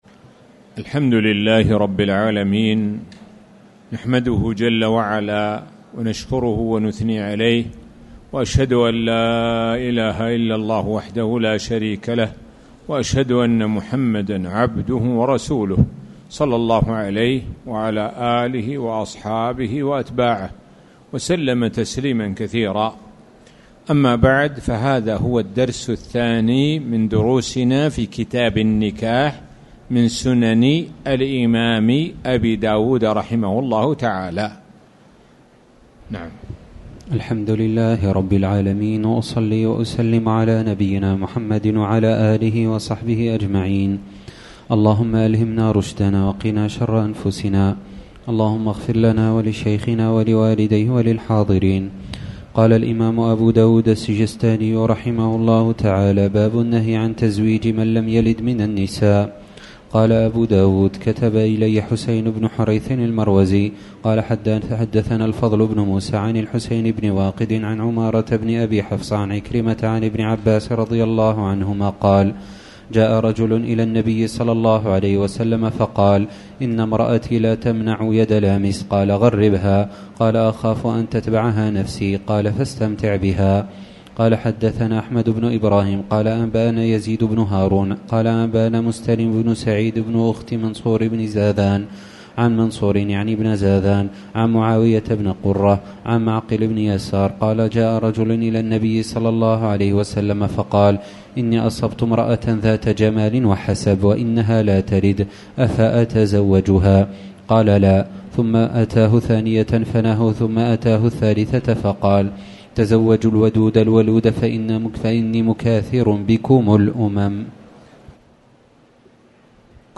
تاريخ النشر ١٦ شوال ١٤٣٨ هـ المكان: المسجد الحرام الشيخ: معالي الشيخ د. سعد بن ناصر الشثري معالي الشيخ د. سعد بن ناصر الشثري كتاب النكاح